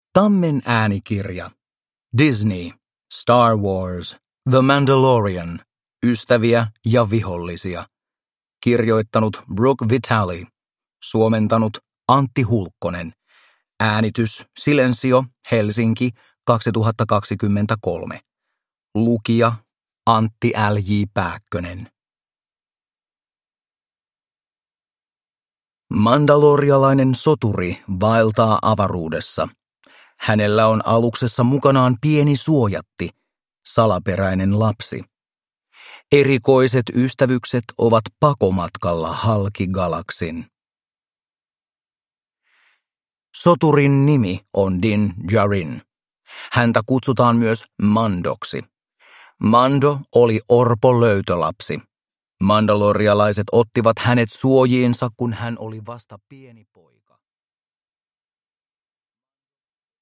Star Wars. The Mandalorian. Ystäviä ja vihollisia – Ljudbok – Laddas ner